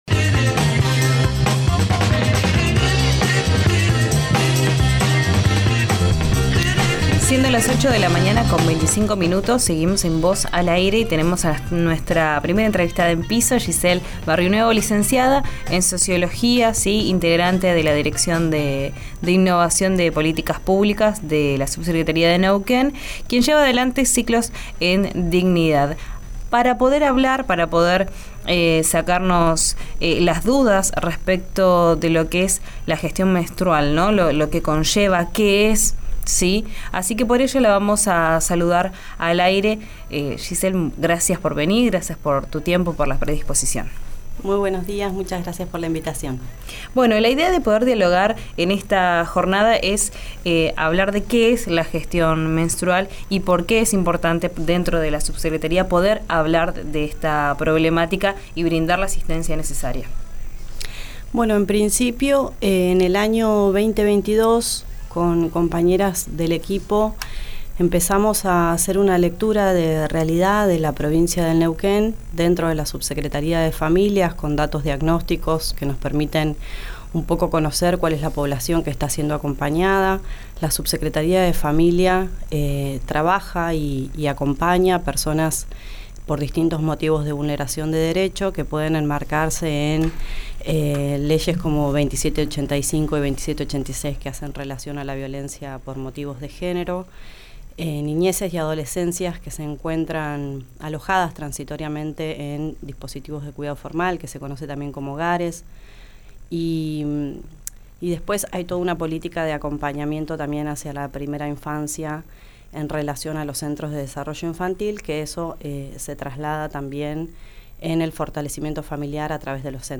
visitó el estudio de RÍO NEGRO RADIO para hablar de menstruación.